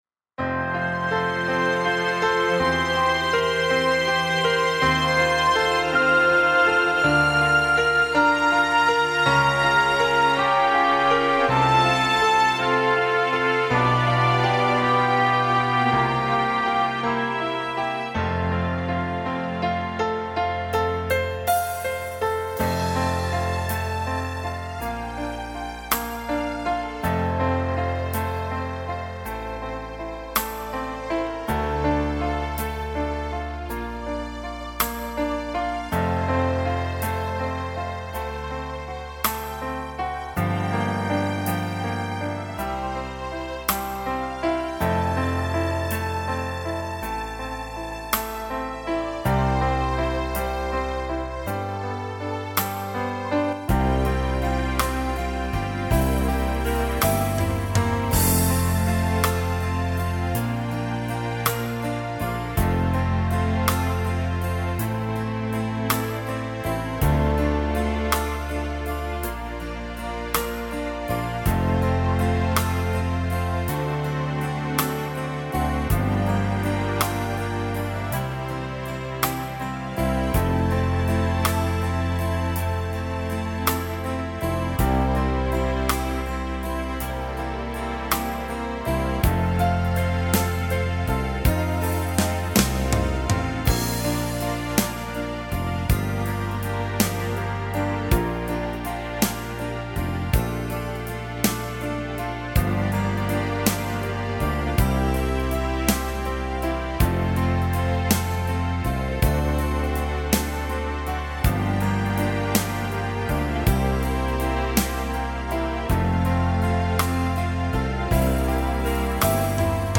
•   Beat  01.